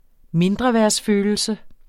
Udtale [ ˈmendʁʌvεɐ̯s- ]